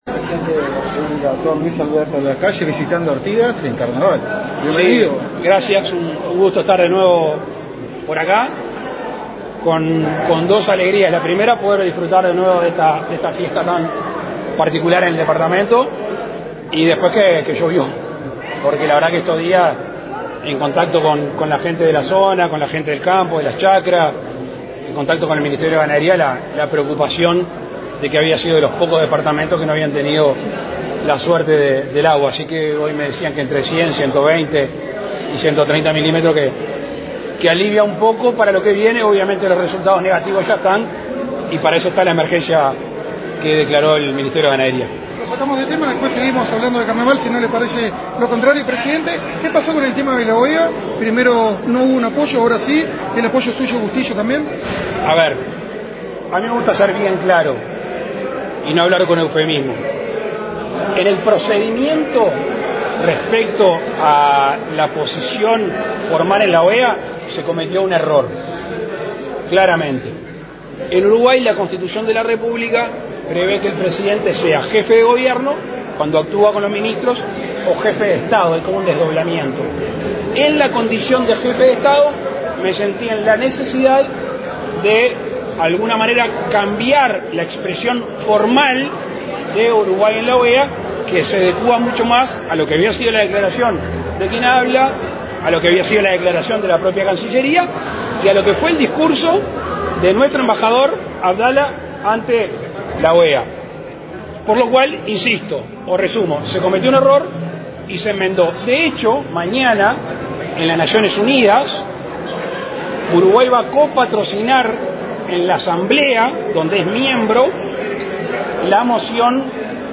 Declaraciones a la prensa del presidente de la República, Luis Lacalle Pou, en Artigas
Declaraciones a la prensa del presidente de la República, Luis Lacalle Pou, en Artigas 01/03/2022 Compartir Facebook X Copiar enlace WhatsApp LinkedIn El presidente Lacalle Pou participó, este 28 de febrero, en la celebración del Carnaval de Artigas 2022. En ese marco, efectuó declaraciones a la prensa.